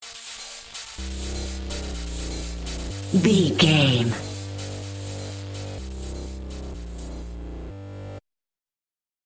Epic / Action
Aeolian/Minor
Fast
dark
futuristic
aggressive
synthesiser
drum machine
house
techno
synth leads
synth bass
upbeat